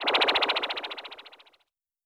Zap FX 002.wav